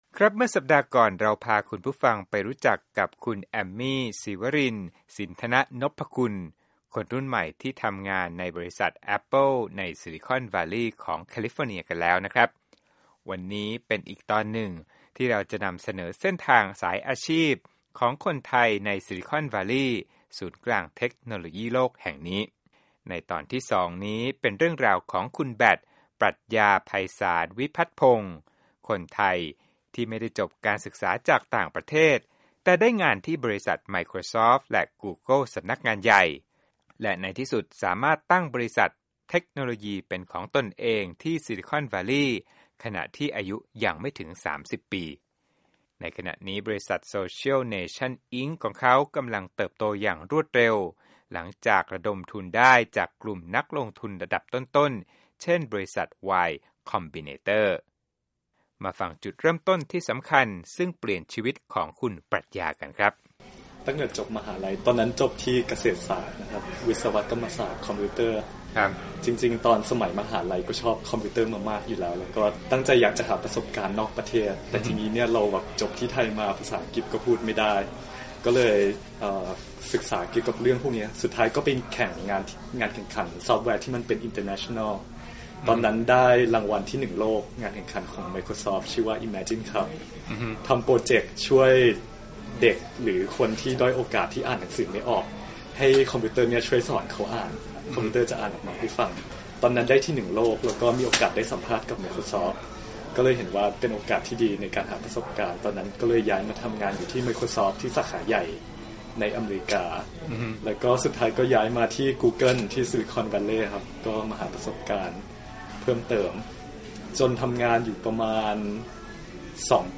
Interview Thai StarttUp